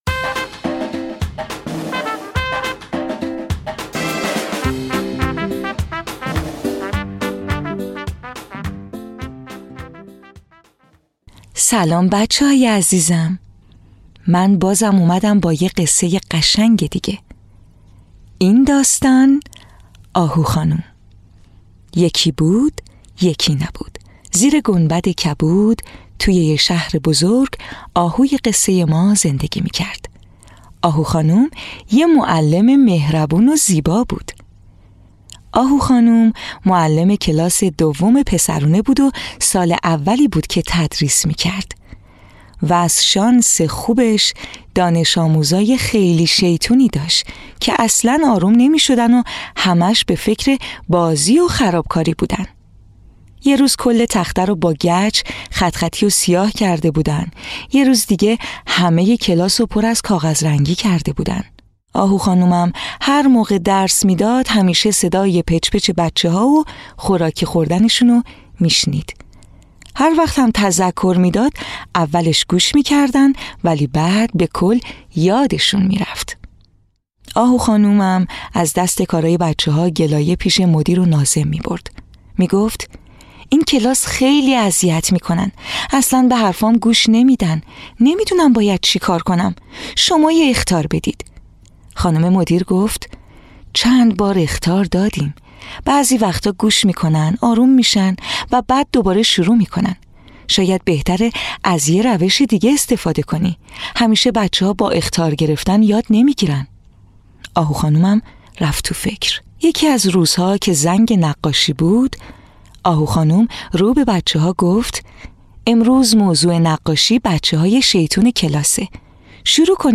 قصه‌های کودکانه صوتی - این داستان: آهو خانم
تهیه شده در استودیو نت به نت